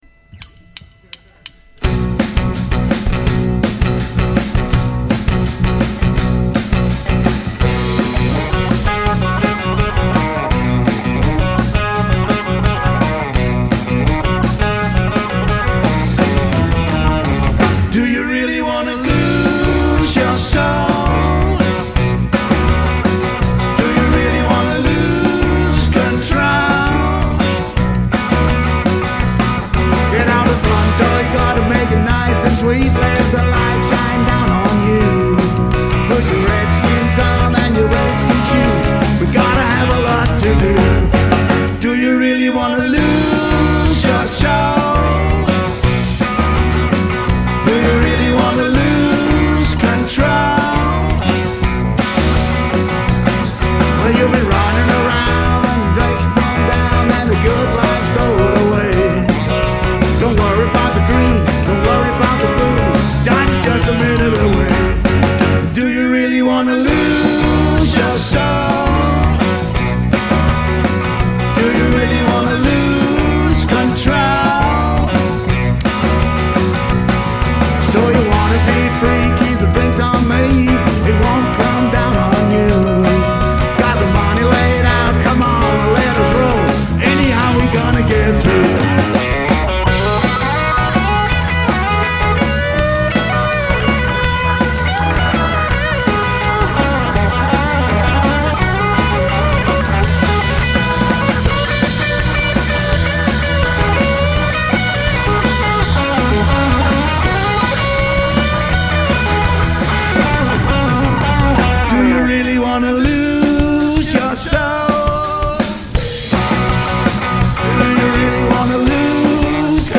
• Blues
• Country
• Singer/songwriter
• Nordisk americana